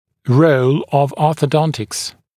[rəul əv ˌɔːθə’dɔntɪks][роул ов ˌо:сэ’донтикс]роль ортодонтии